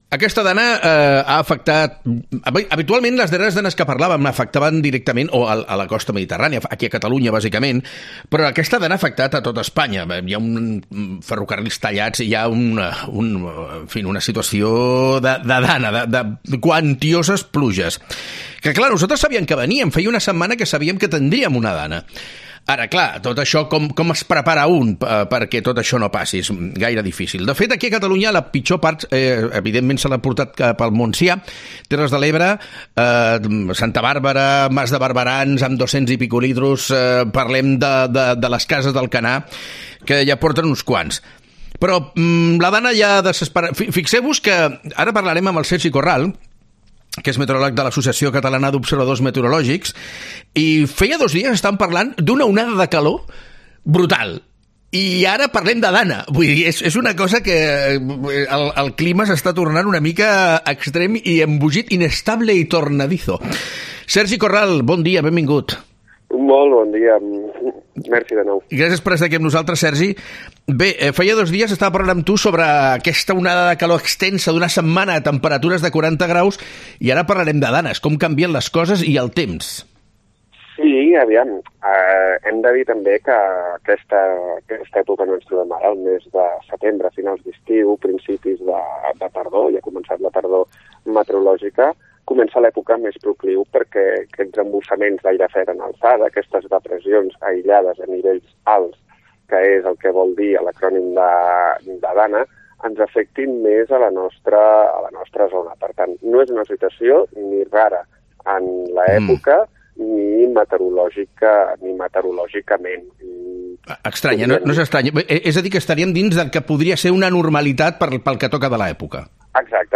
XERRADA